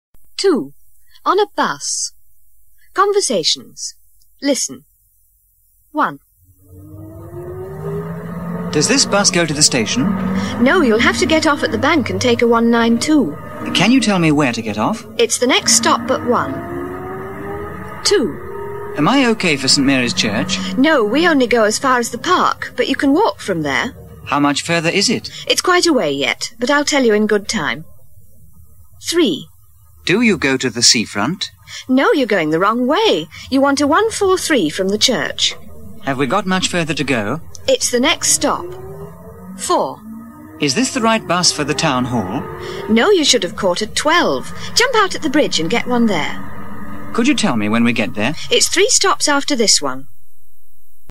مجموعه مکالمات اجتماعی زبان انگلیسی – درس شماره دوم: در اتوبوس
برای یادگیری هر چه بهتر این مکالمه، ترجمه فارسی به همراه فایل صوتی مکالمه مورد نظر را برای شما همراهان عزیز وبسایت کاردوآنلاین آماده کرده ایم.